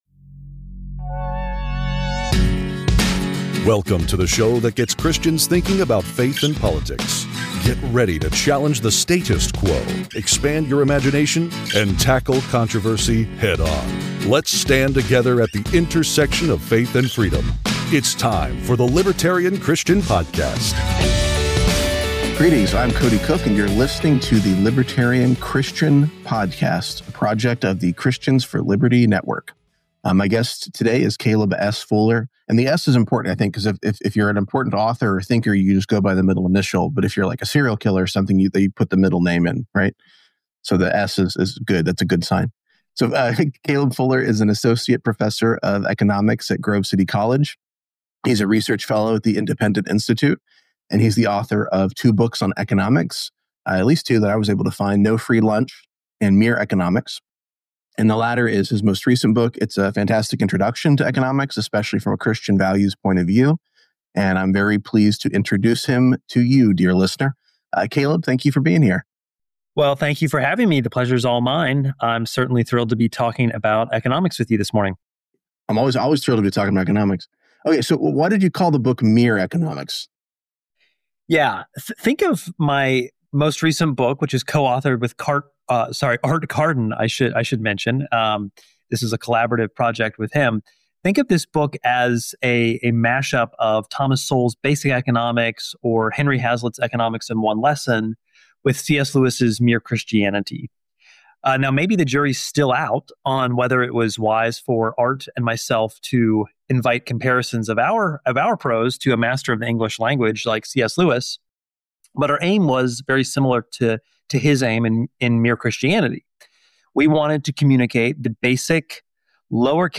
The conversation takes a deep dive into the role of markets, trade, and private property, as well as common misunderstandings about self-interest, selfishness, and the supposed morality of government intervention.